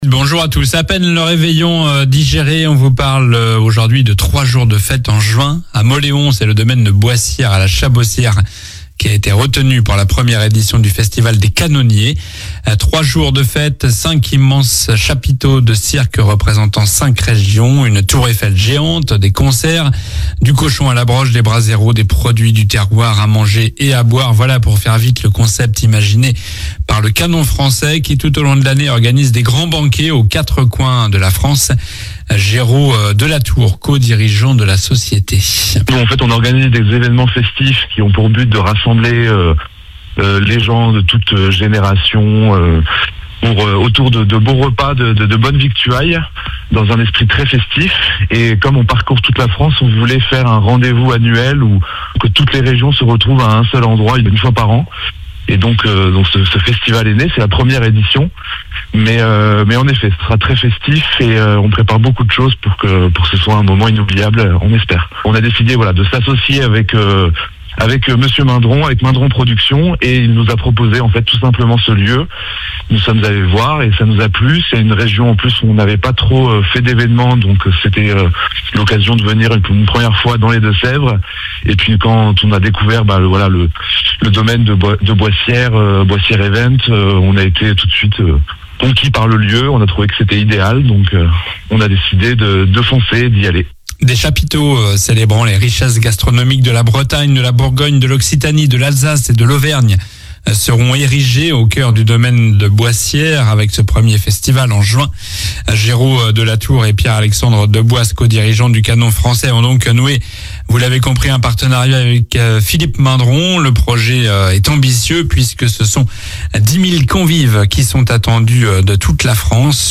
Journal du samedi 4 janvier (matin)